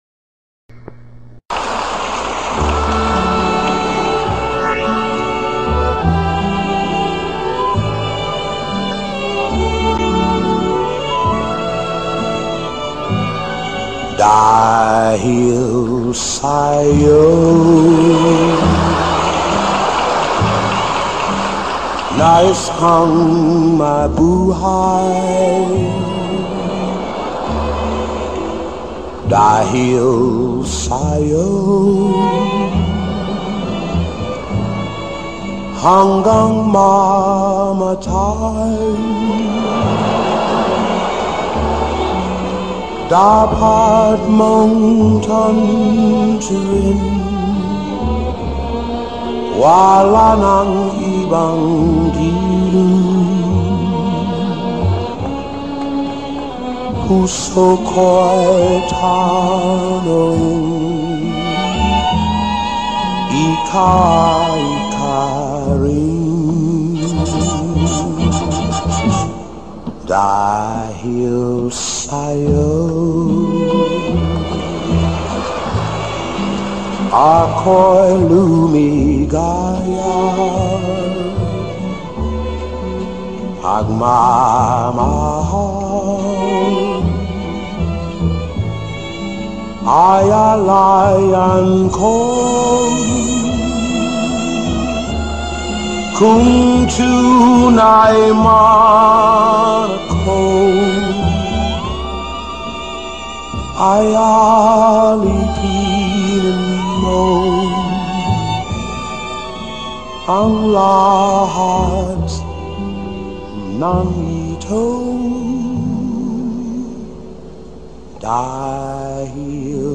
Filipino Songs